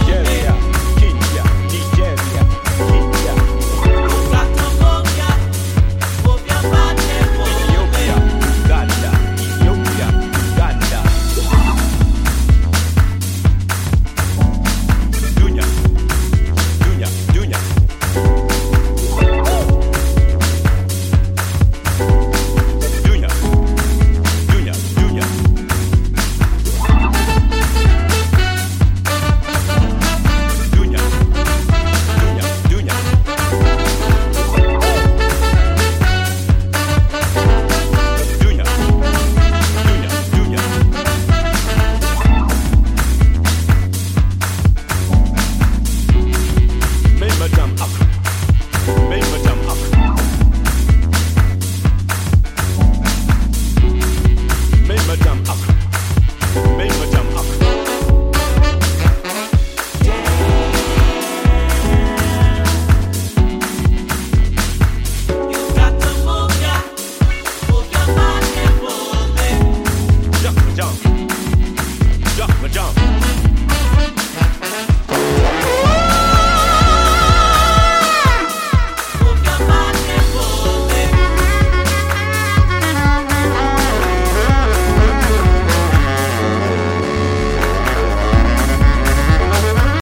Modern soul / funk band
Deep house